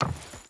Wood Chain Run 3.ogg